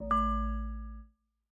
steeltonguedrum_a.ogg